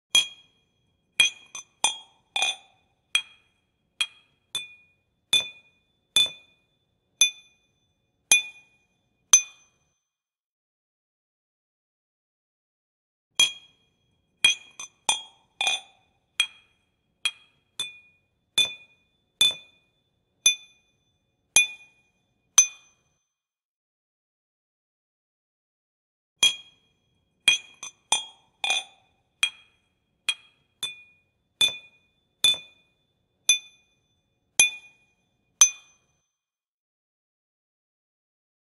Clinking Glasses Sound Effect Free Download
Clinking Glasses